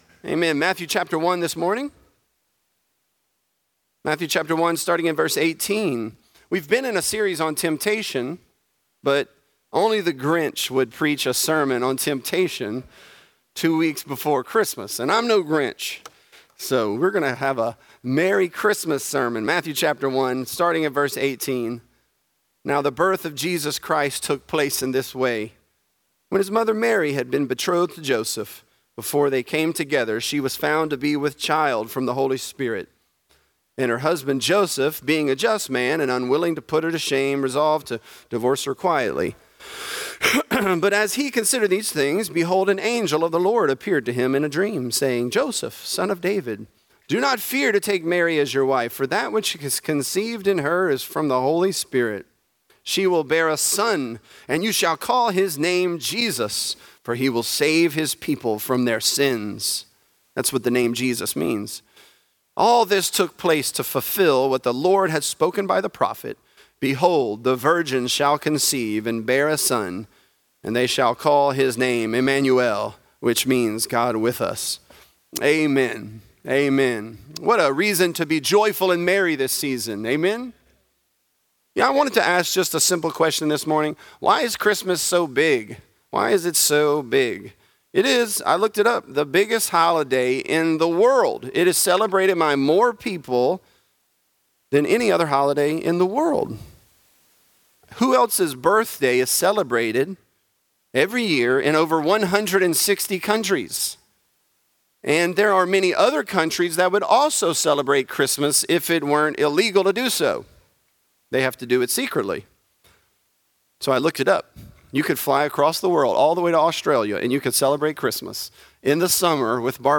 A Global Christmas | Lafayette - Sermon (Matthew 1)